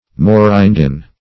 morindin \mo*rin"din\, n. (Chem.)